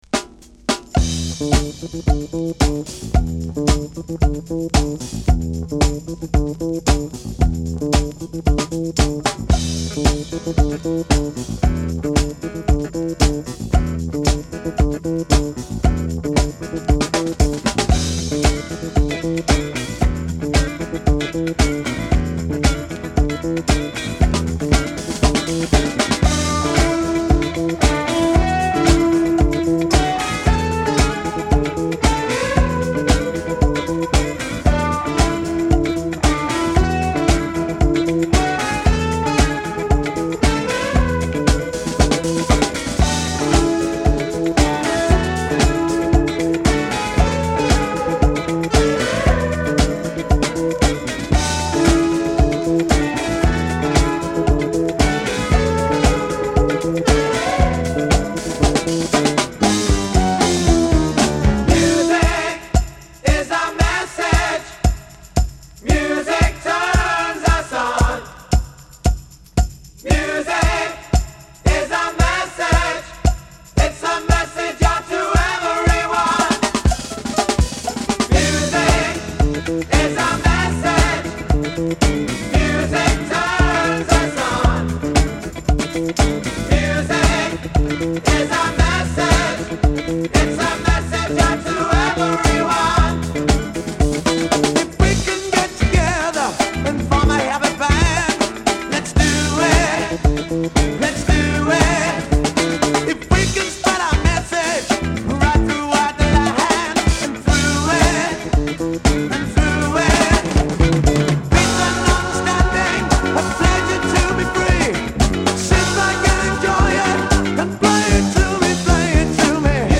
タイトなファンクチューン